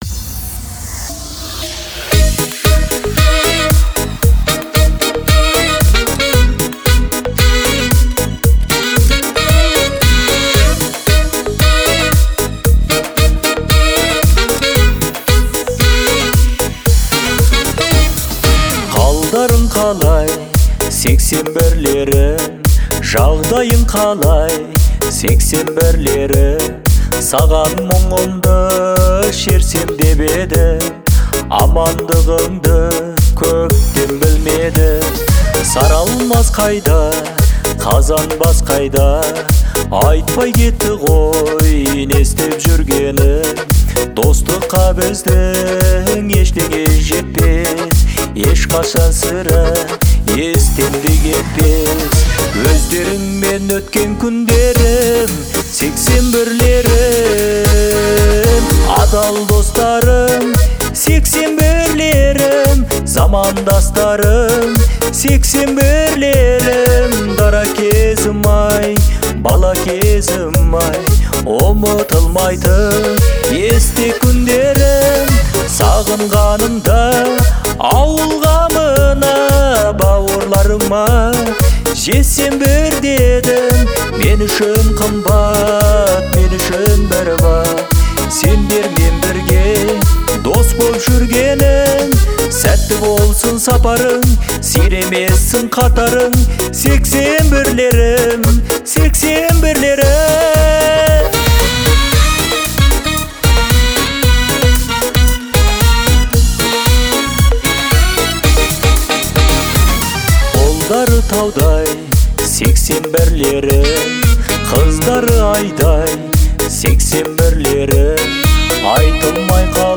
который сочетает в себе элементы поп и традиционной музыки.